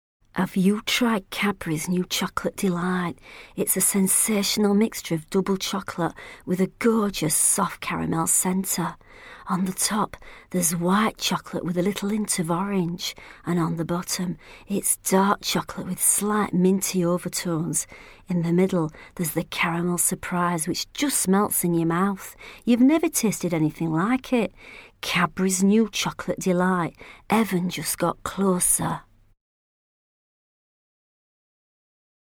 Sprechprobe: Sonstiges (Muttersprache):
Very easy to work with, takes direction very well, adaptable, flexible, diverse, range of styles from Warm, Friendly, Sexy to Serious, Sad, Corporate.